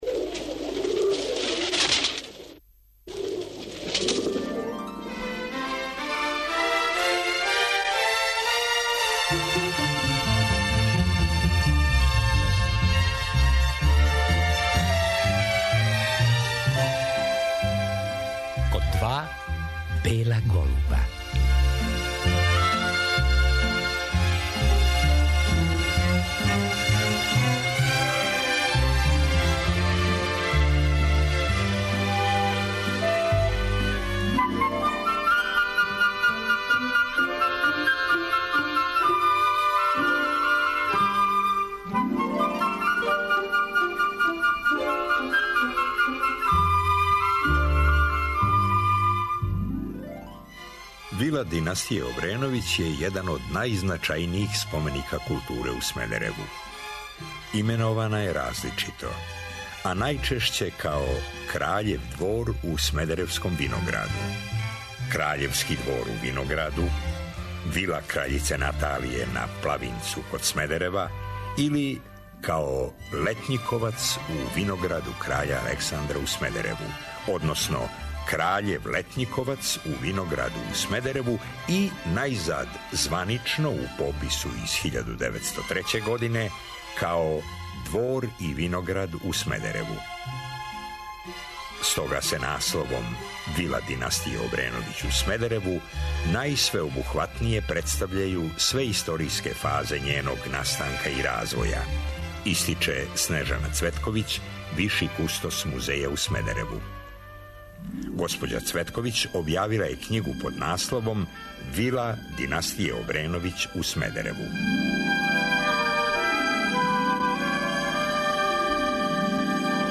Радио Београд 1, 20.00